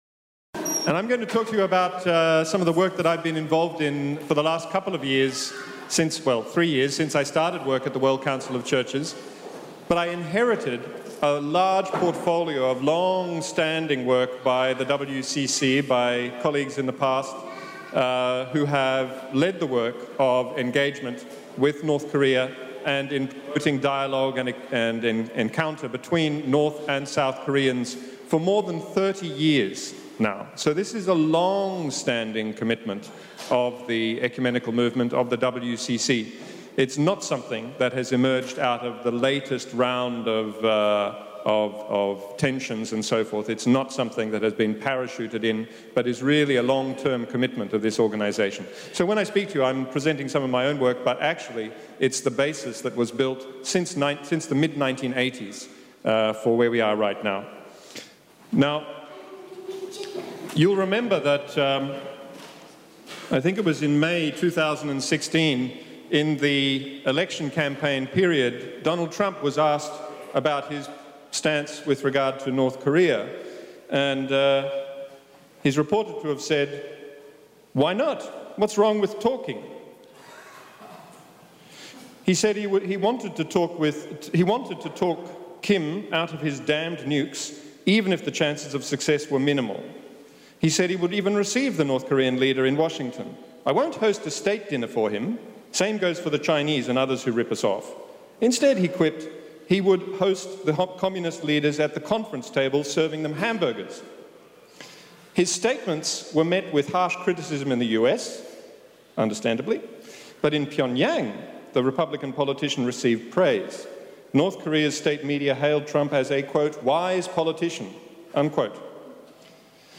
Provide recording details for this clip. Weekly Forums at ELCG – after worship, in the Sanctuary – 12:45 to 13:30